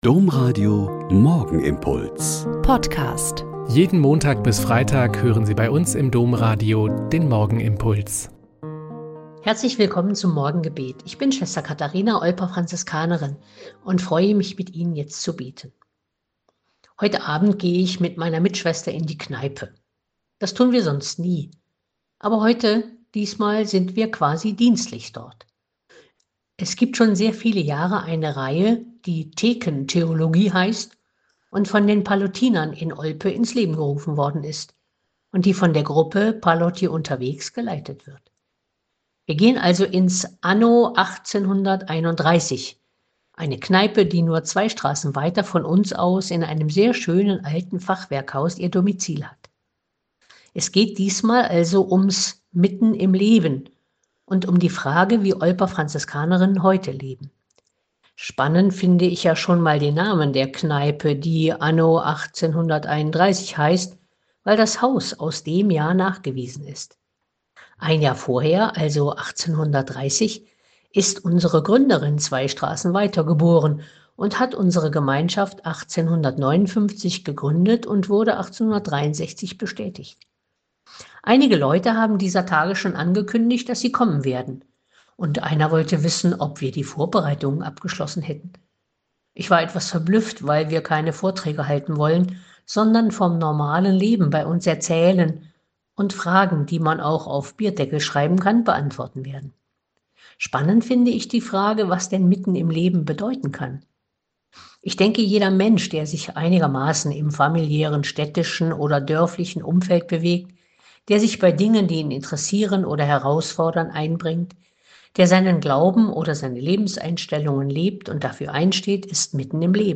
Mk 3,7-12 - Gespräch